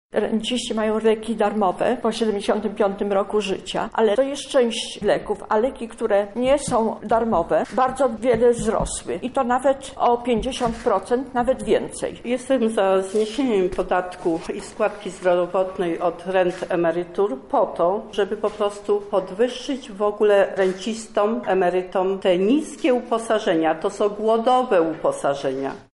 O tym, jak wygląda obecna sytuacja rent i emerytur mówią sami zainteresowani: